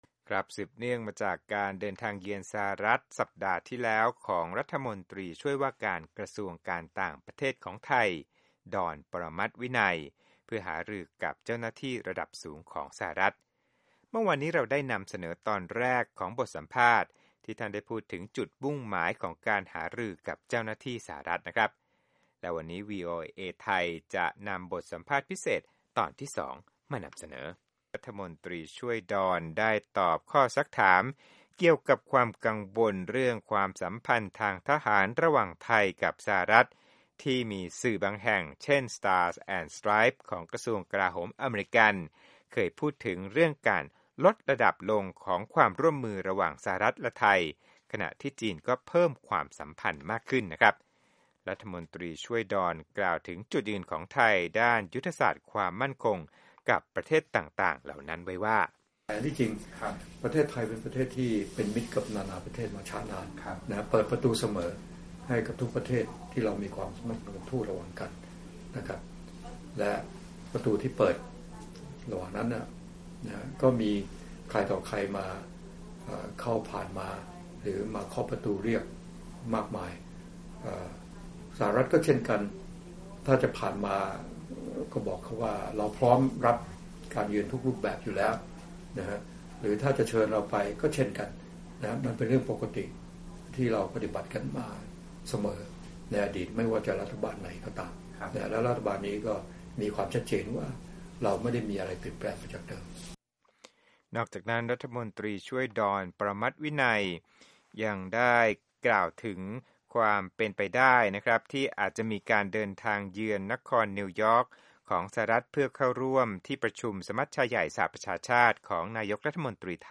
สัมภาษณ์พิเศษตอนที่ 2: รมช. ต่างประเทศ ดอน ปรมัตถ์วินัย